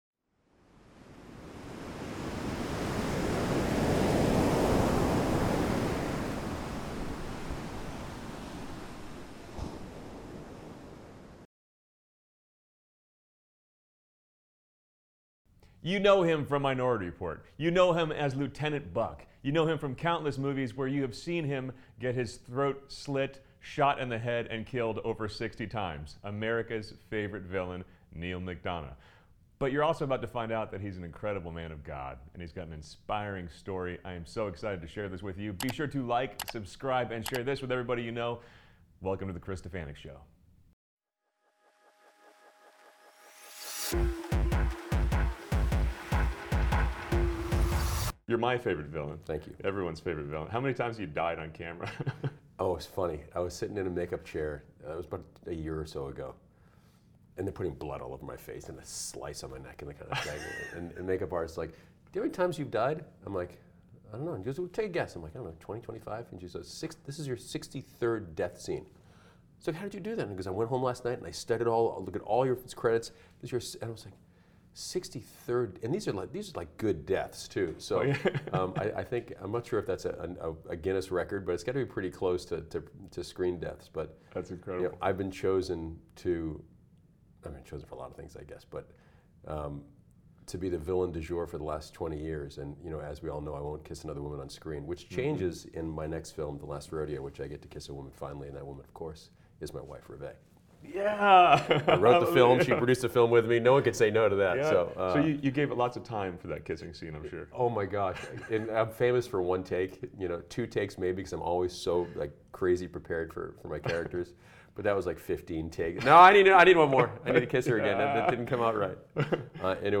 My conversation with Neal is so inspiring. We talk about his career, how he nearly lost everything after taking a stand, his struggles with addiction during that dark time, and how he came back, better than ever, to have an extensive and AMAZING career, using his talents to glorify